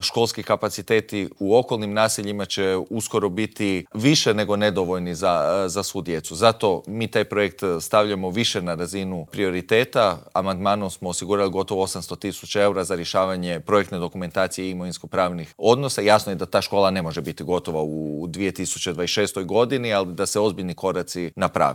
O ovim i drugim pitanjima smo u Intervjuu tjedna Media servisa razgovarali s predsjednikom zagrebačke Gradske skupštine, Matejem Mišićem.